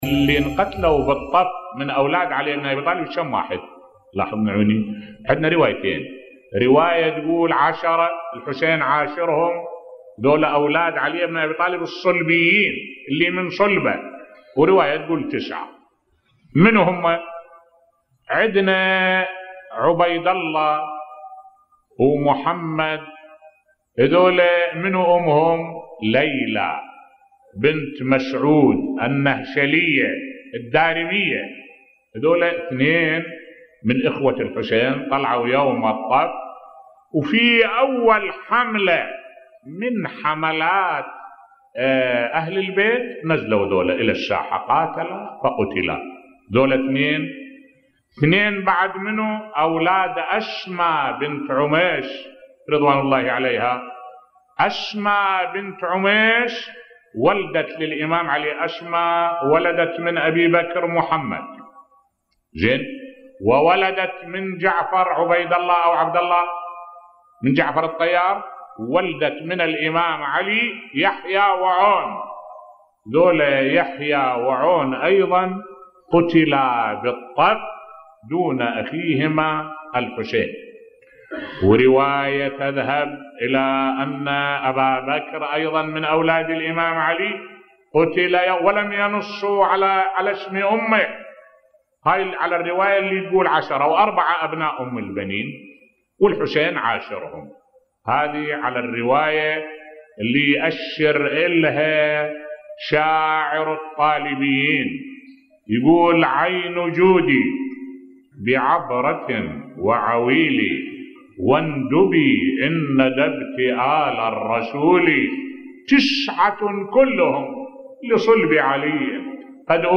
ملف صوتی اولاد علي عليه السلام الذين استشهدوا مع اخيهم الحسين عليه السلام بصوت الشيخ الدكتور أحمد الوائلي